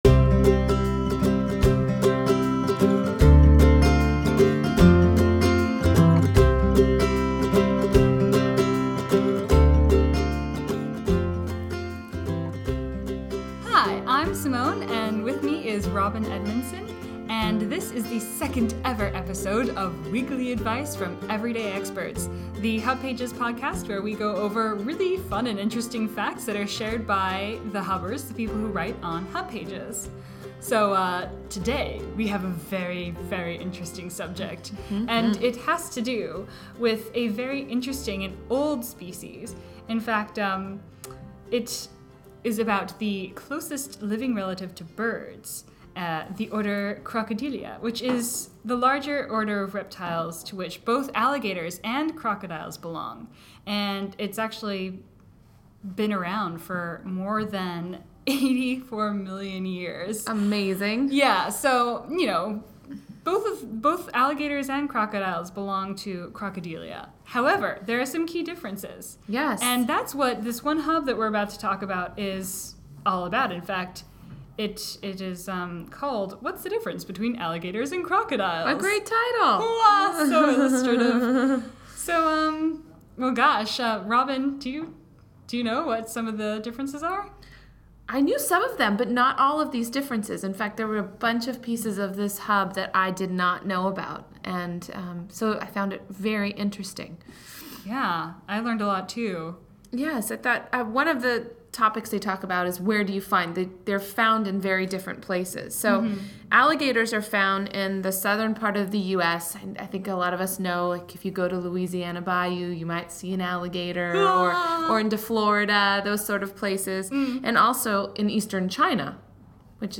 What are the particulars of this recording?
In the name of podcaster-integrity, we even ventured into peril-ridden swamps to hear from these living fossils directly.